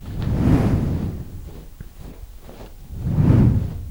“Dragon Wings Flapping” Created in Sound Booth CS4 By
dragon_wings_flappin3.wav